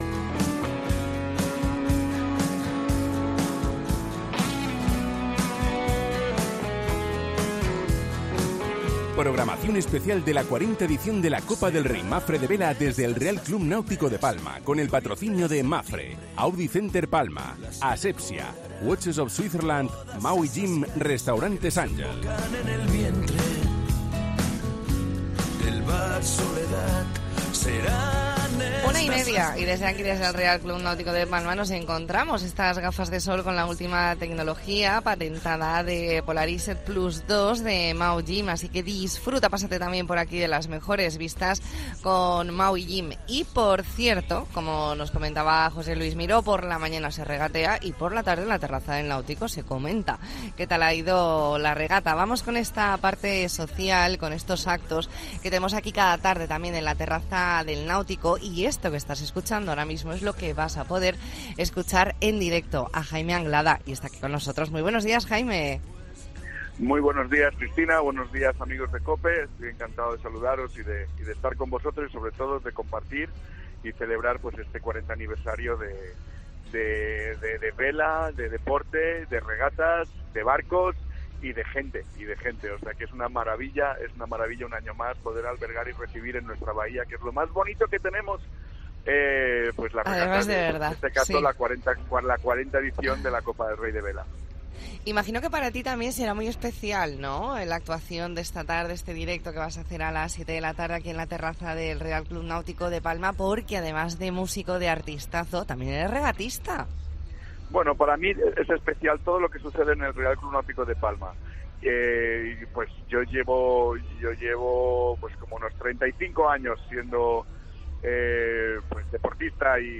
Especial La Mañana en COPE Más Mallorca desde el RCNP con motivo de la 40 Copa del Rey Mapfre